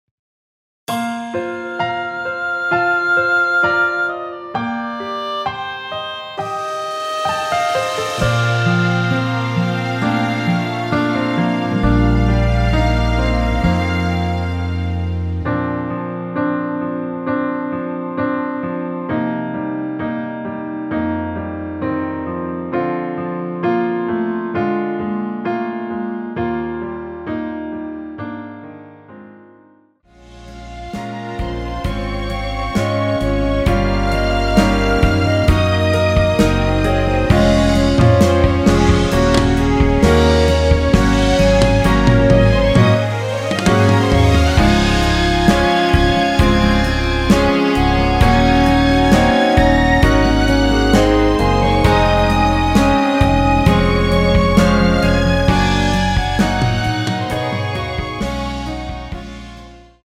원키 1절 + 후렴으로 편곡한 멜로디 포함된 MR 입니다.(미리듣기및 가사 참조)
Bb
앞부분30초, 뒷부분30초씩 편집해서 올려 드리고 있습니다.
중간에 음이 끈어지고 다시 나오는 이유는